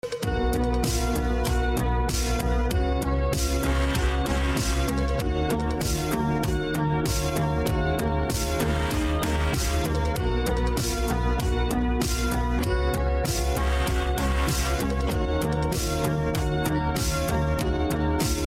Прошу помочь накрутить такой звук основного лида.
замедленный семпл